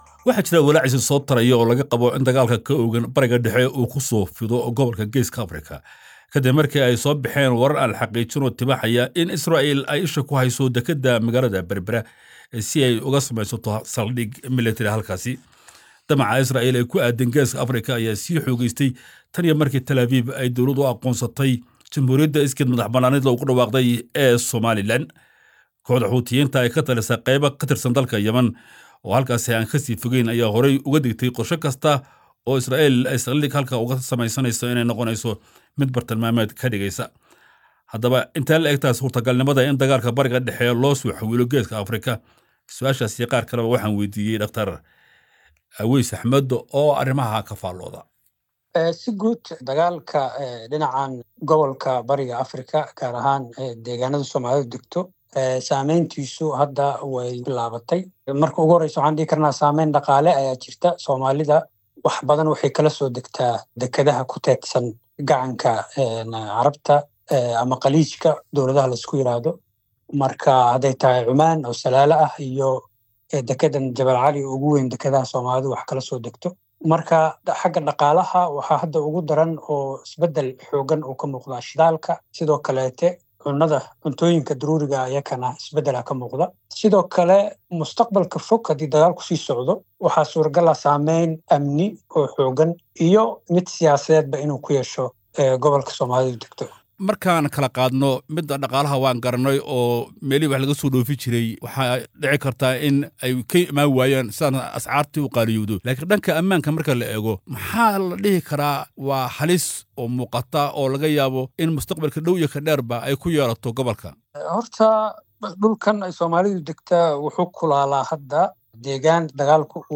Wareysi: Walaaca Laga Qabo in Dagaalka Bariga Dhexe ku Faafo Waddamada Geeska Afrika